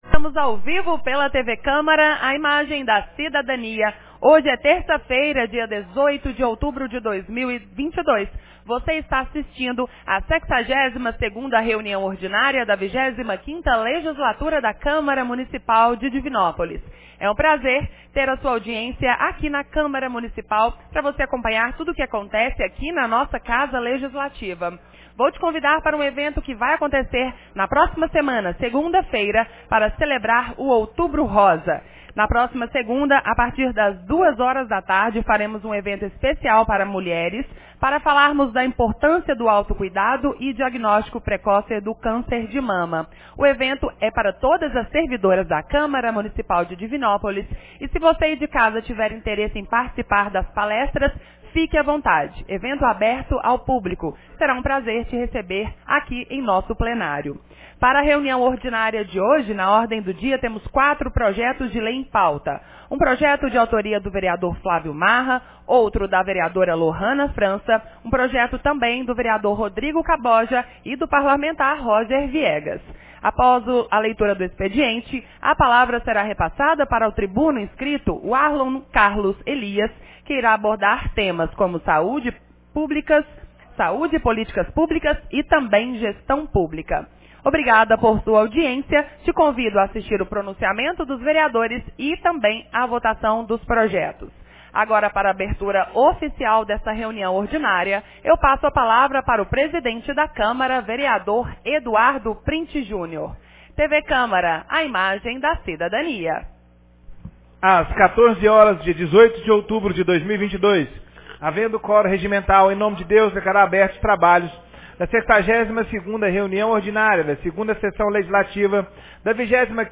62ª Reuniao Ordinária de 18 de outubro 2022